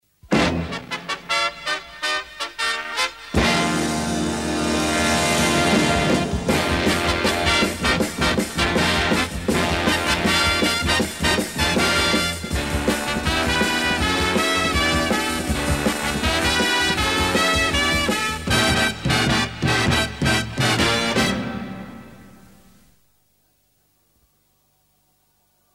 Sounds like a talk show intro
I was thinking more 70’s cop show.